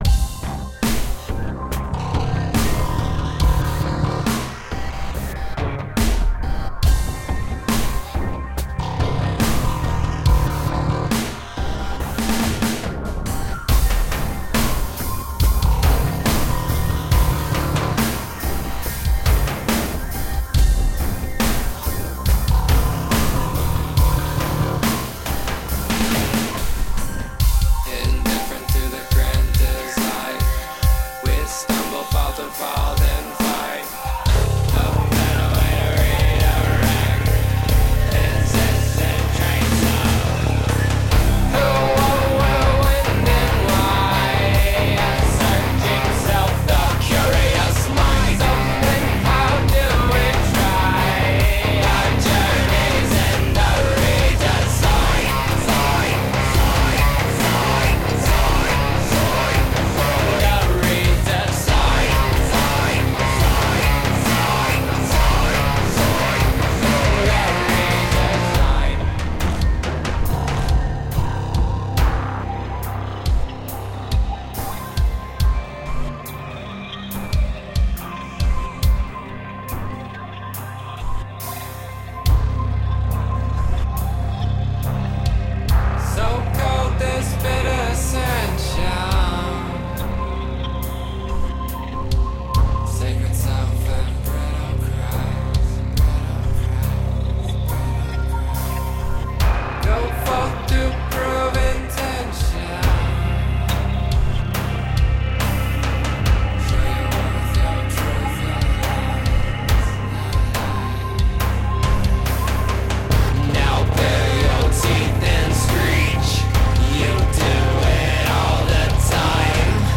guitar
the chunking guitar rhythms
After some solid studio production
6 string bass guitar